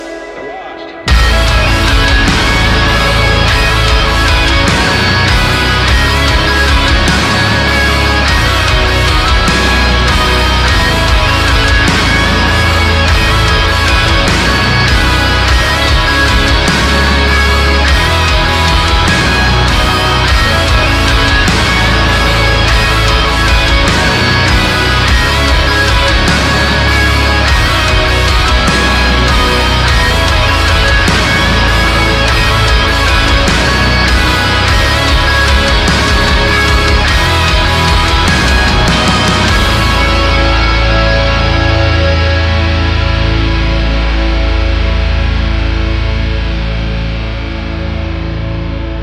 • Качество: 320, Stereo
громкие
Metalcore
мелодичные
без слов
инструментальные
Instrumental Core
Отличное соло подойдет как на будильник, так и на рингтон